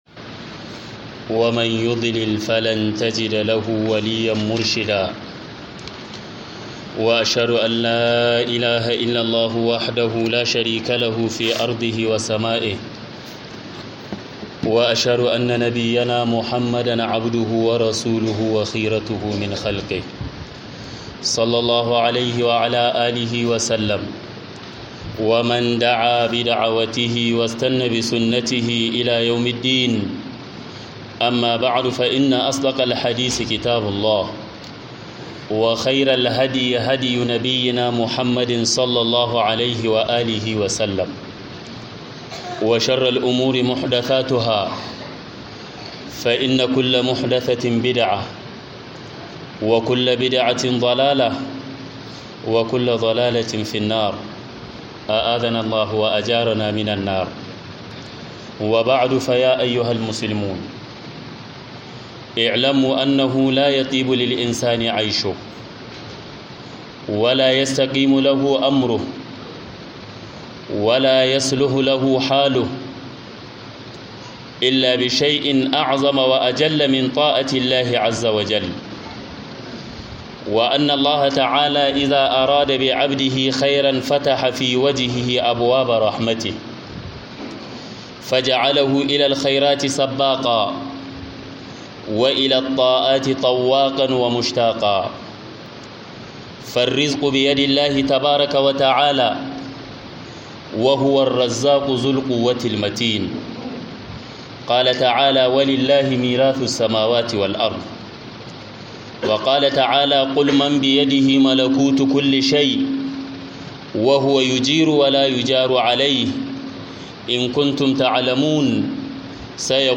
SABUBBAN-DA-KE-SA-ALLAH-YA-YALWATA-ARZIKI-KUMA-YA-DAWAMAR-DACHI - MUHADARA